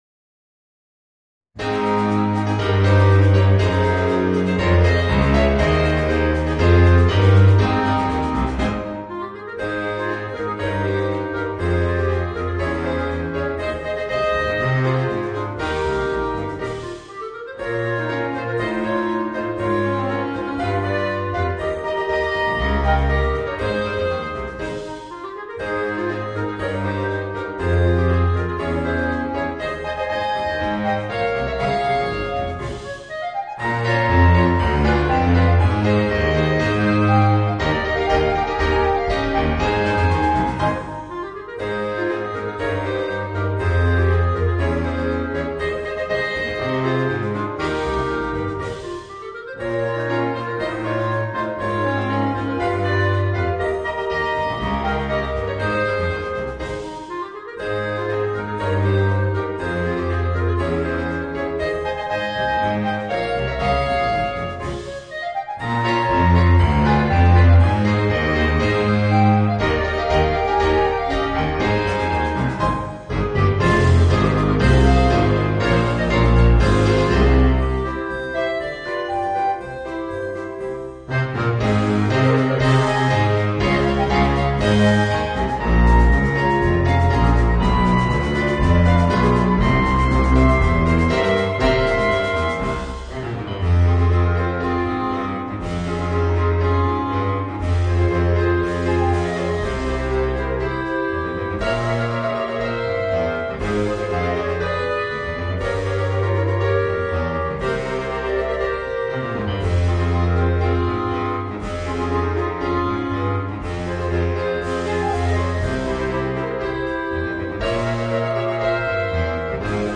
Voicing: 4 Clarinets and Rhythm Section